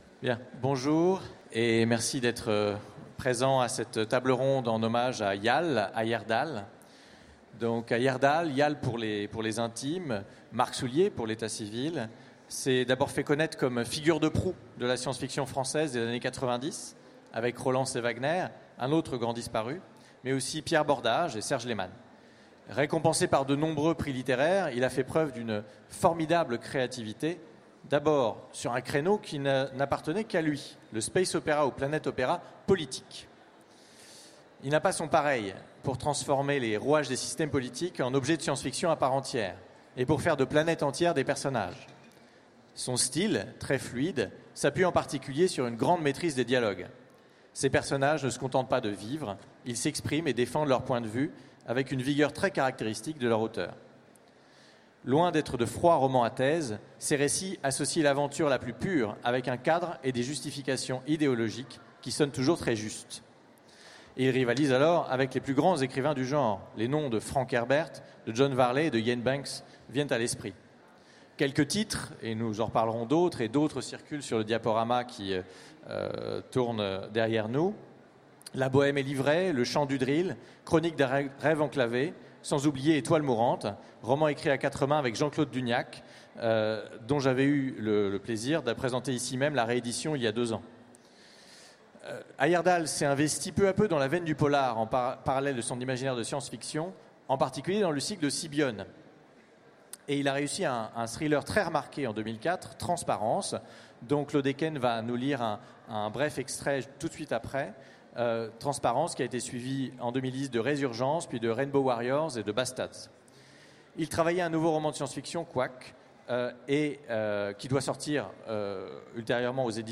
Mots-clés Hommage Conférence Partager cet article